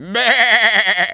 sheep_baa_bleat_angry_01.wav